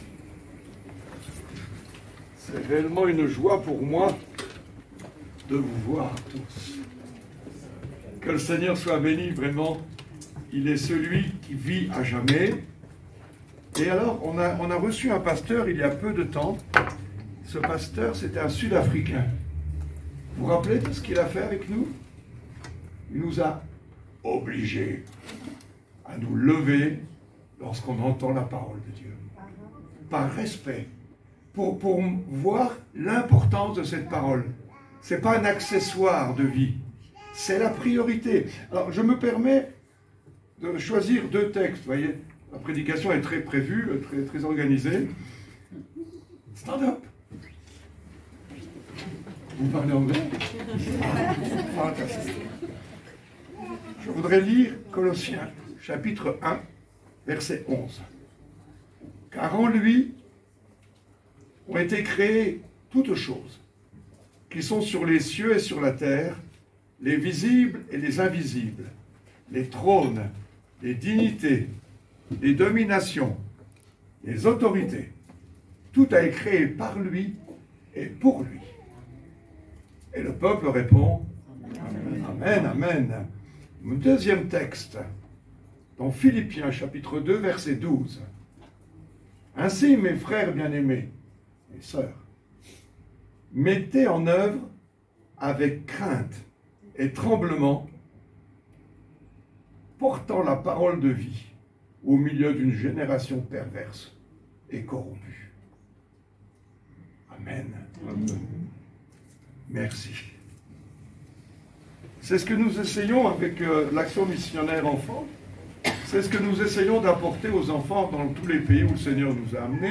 Prédication du 15 juin 2025